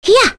Lewsia_A-Vox_Attack1.wav